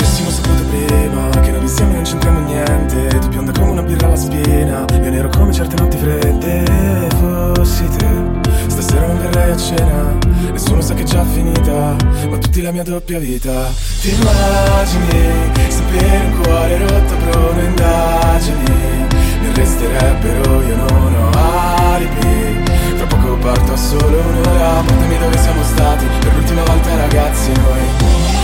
Genere: pop,disco,trap,rap,dance,hit